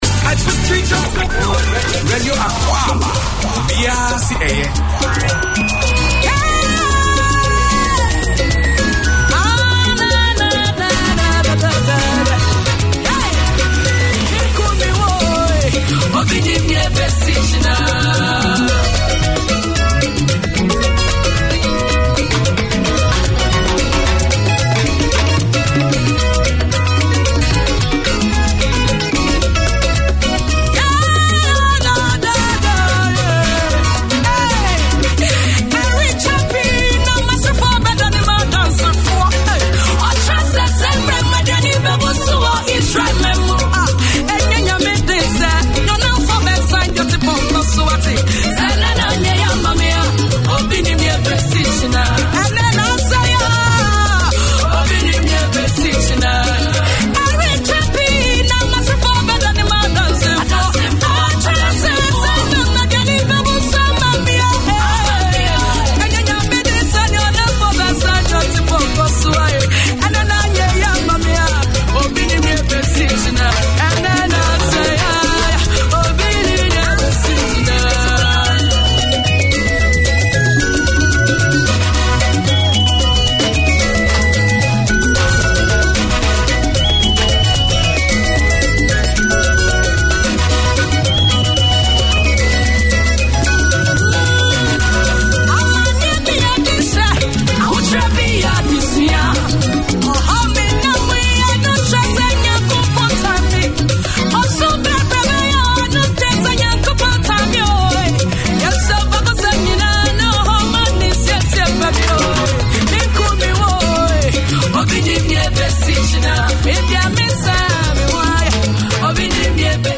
Radio Akwaaba is a programme targeted to the Ghanaian Community and all those who are interested in Ghana or African culture. It brings news, current affairs and sports reporting from Ghana along with music and entertainment.